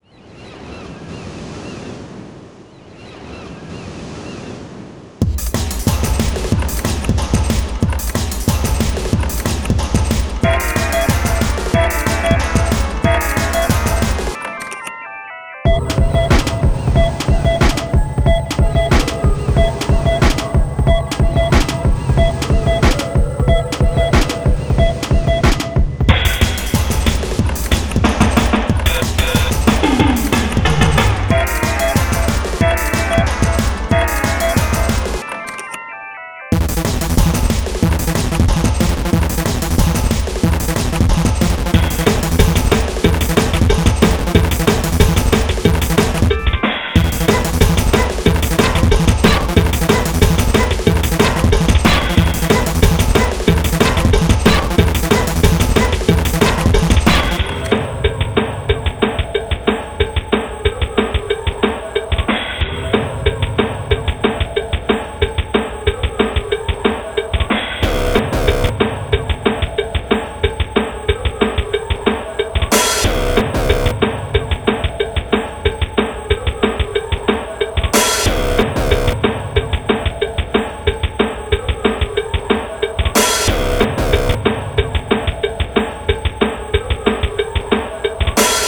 i just like overlapping beeping sounds okay
glitchy noise
Music / Trance
breaks confusing ambient rave